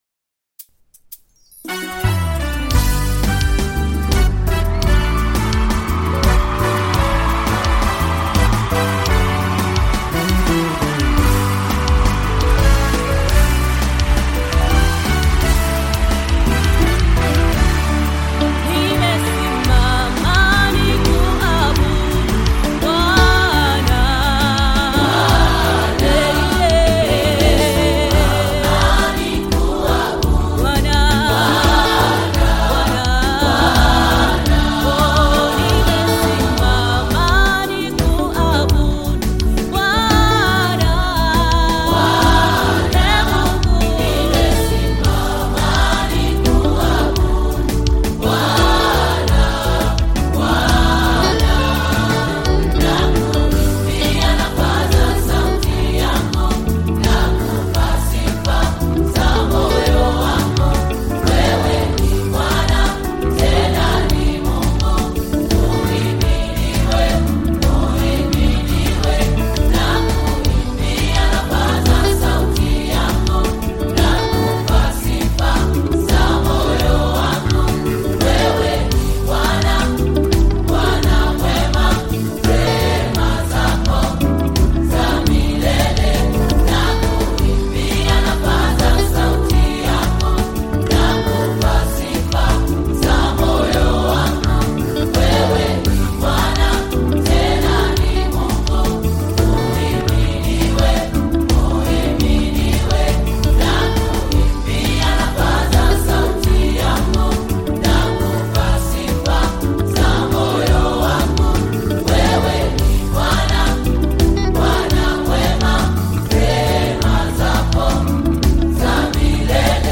Tanzanian gospel choir
praise song
gospel song
African Music